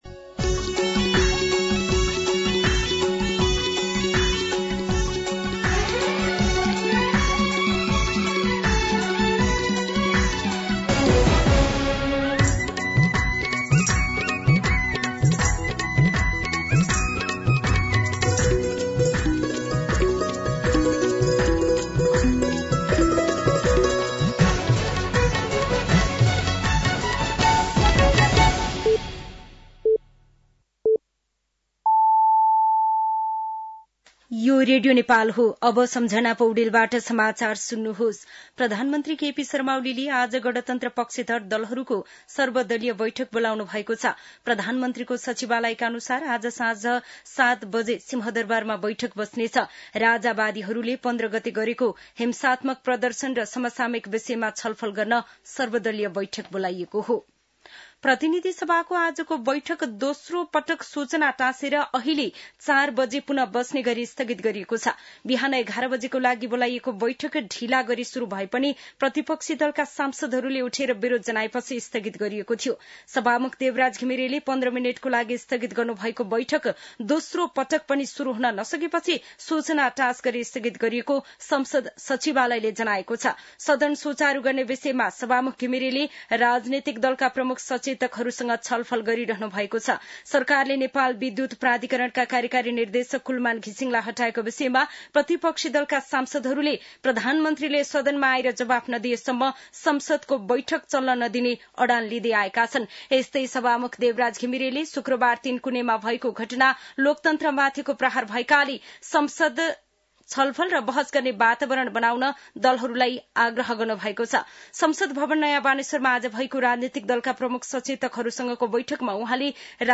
दिउँसो ४ बजेको नेपाली समाचार : १७ चैत , २०८१